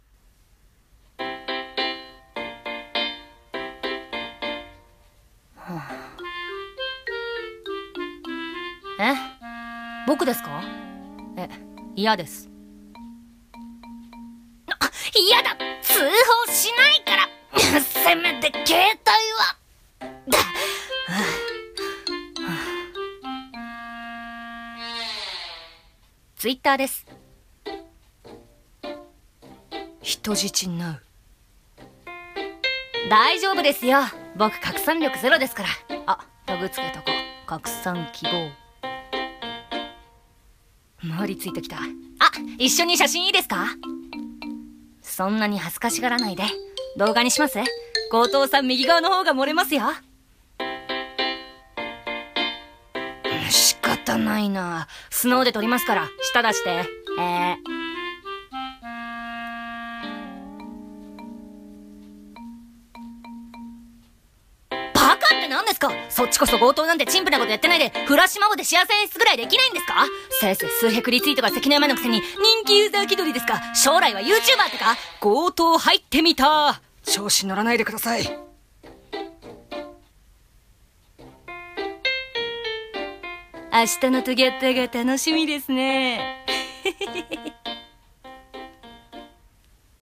【ギャグ声劇】強盗と人質